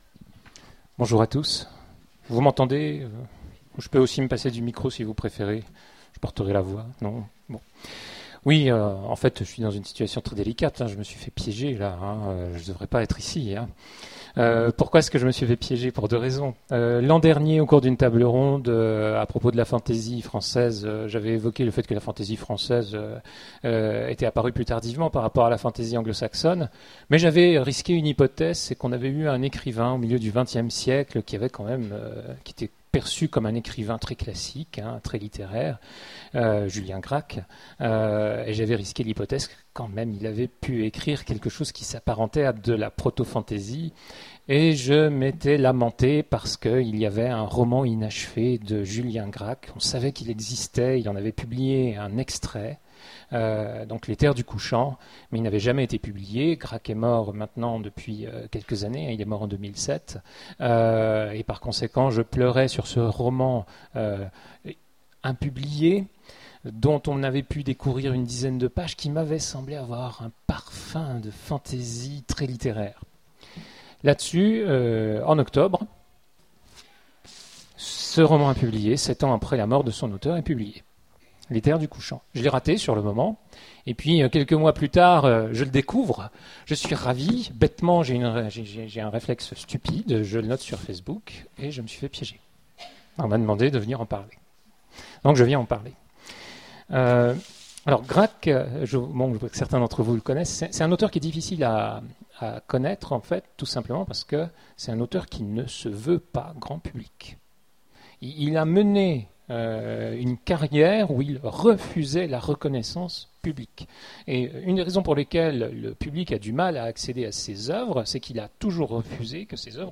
Imaginales 2015 : Conférence Julien Gracq